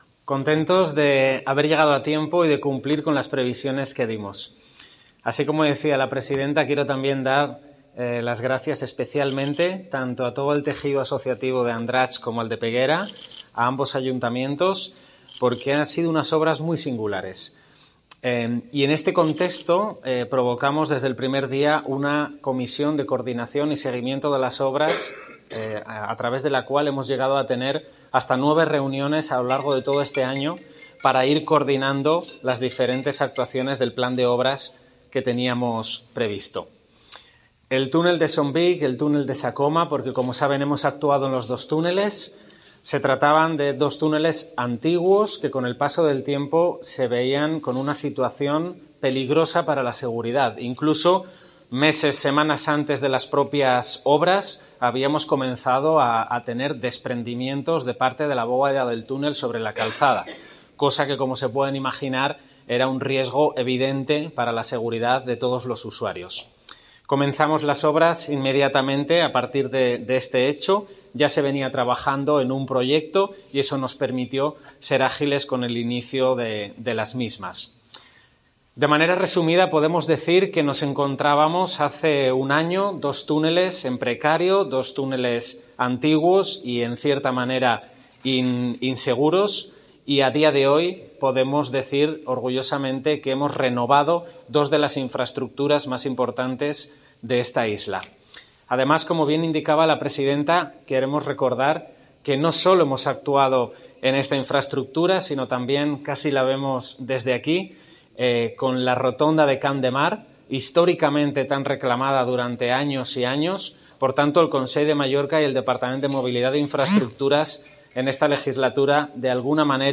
Declaraciones Catalina Cladera
Declaraciones